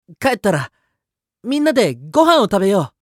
青年ボイス～ホラー系ボイス～